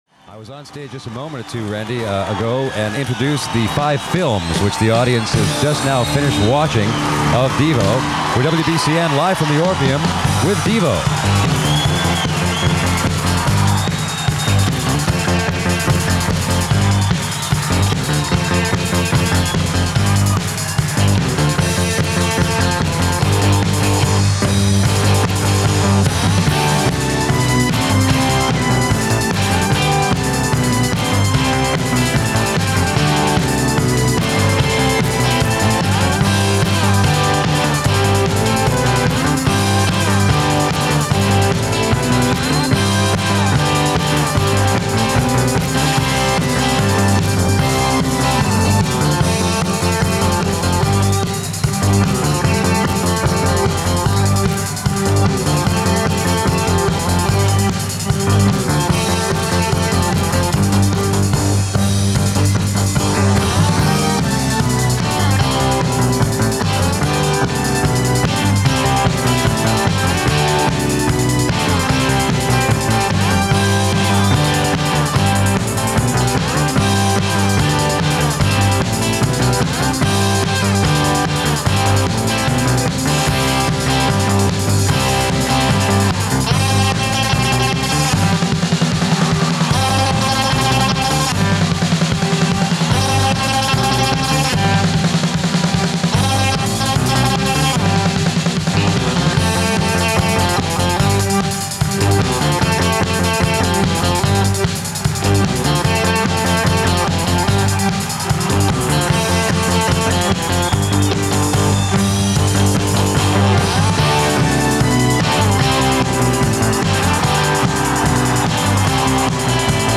reedom Of Choice Theme (Live 1981)}